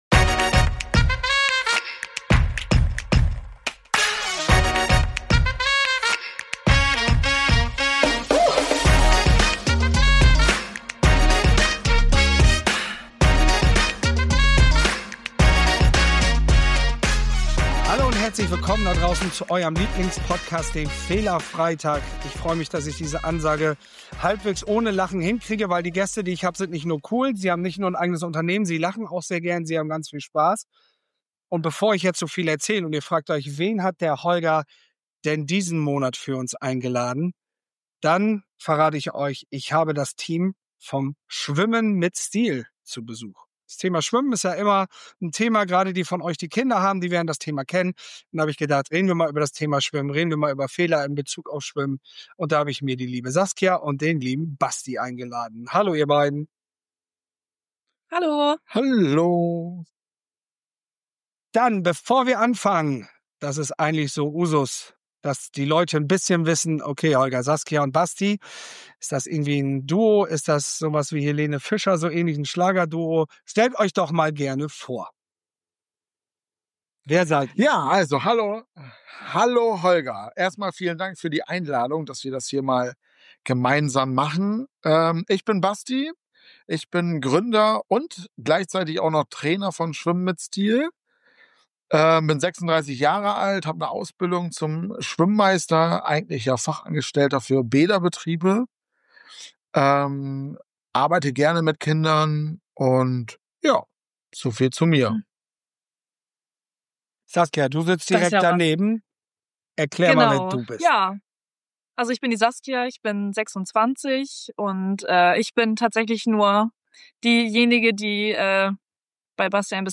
Keine Angst vorm Untergehen: Schwimmen, Scheitern&Selbstvertrauen – Interview